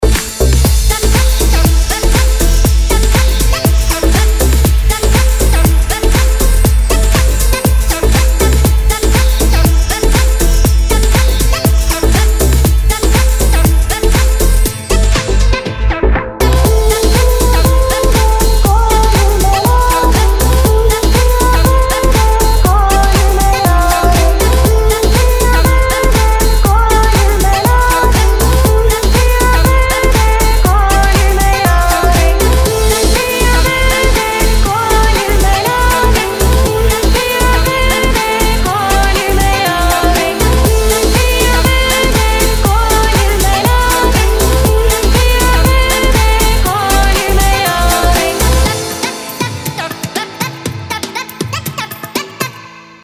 красивые
deep house
восточные мотивы
женский голос
nu disco
Indie Dance